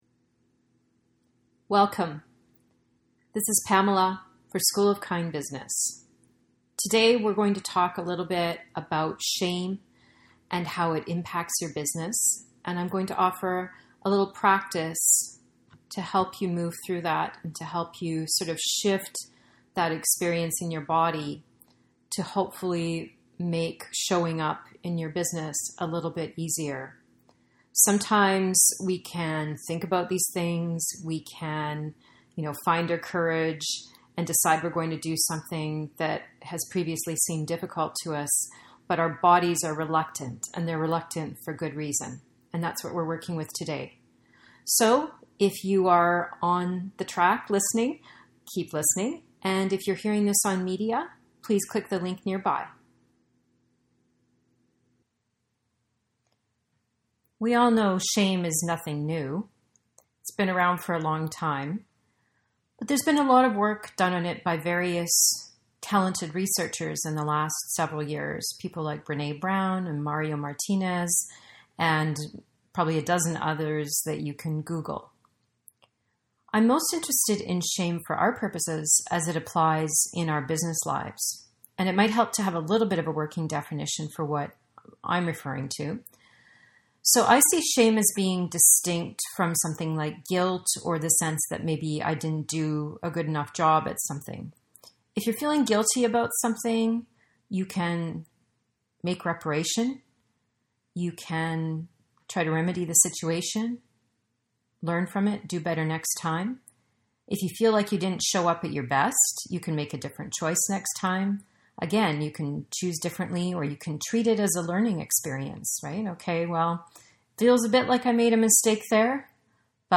Asking for Support: A Visualization for if You are Culturally Allergic to Asking for Help, on a Budget, or Don't Know Who to Ask